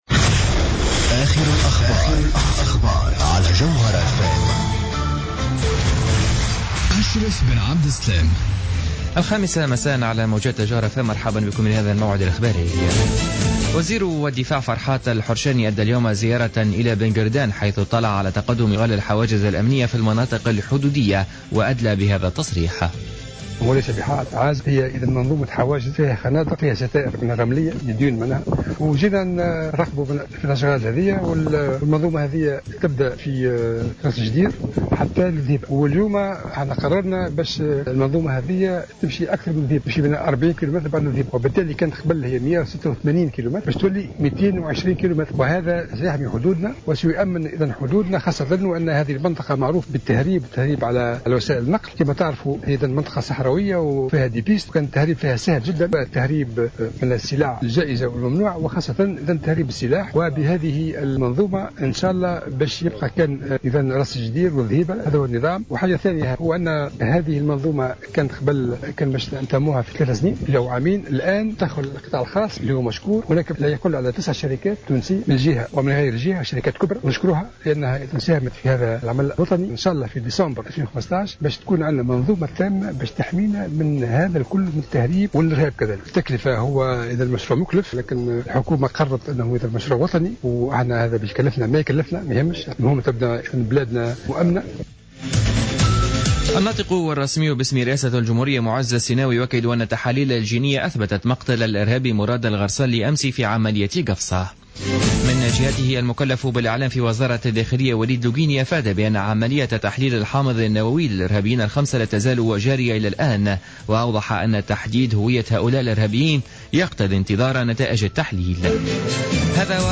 نشرة أخبار الساعة الخامسة مساء ليوم السبت 11 جويلية 2015